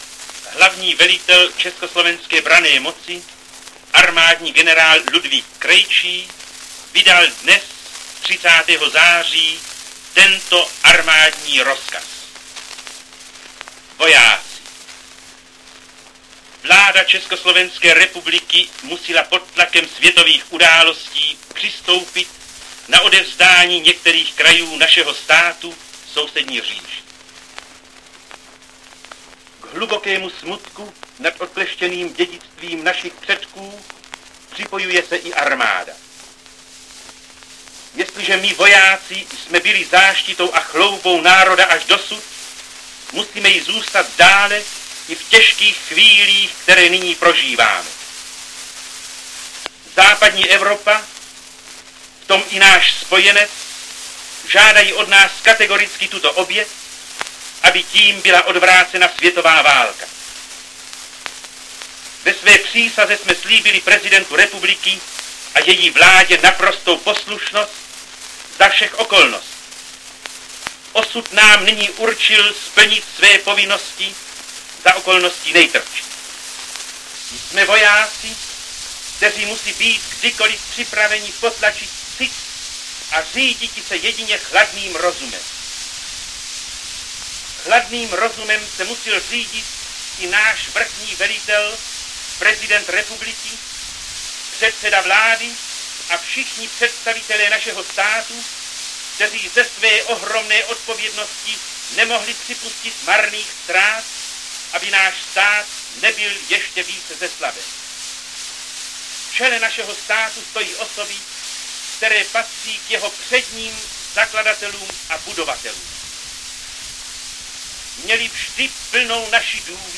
Hlasatel čte rozkaz hlavního velitele čs. branné moci armádního generála Ludvíka Krejčího dne 30. září 1938: